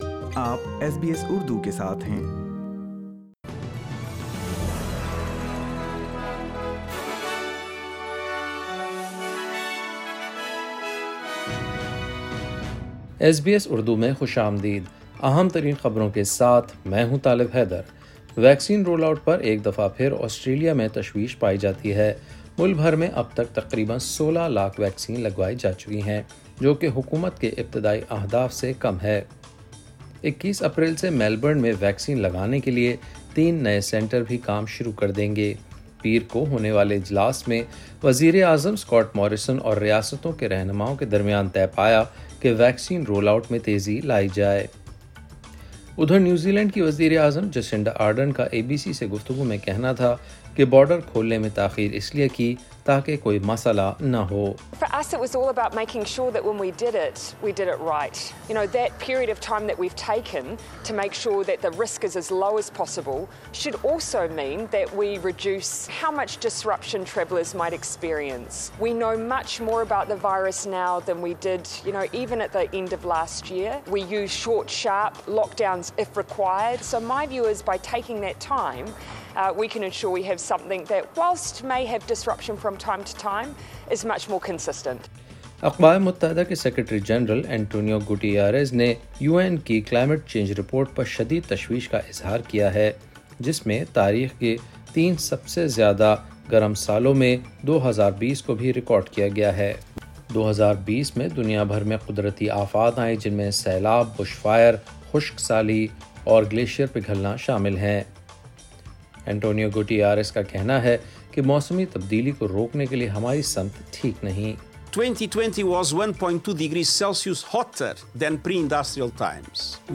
In this bulletin, mass vaccination sites planned to boost the nation's COVID-19 vaccine roll out ....